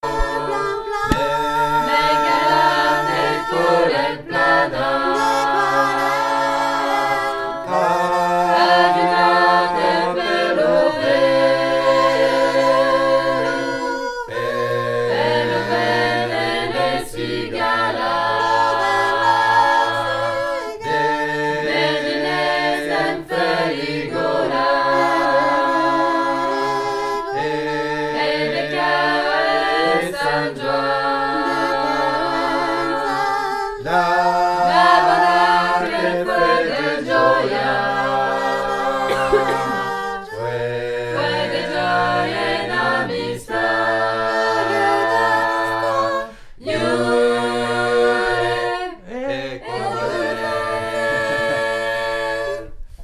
Aquel_cant_Milieu.mp3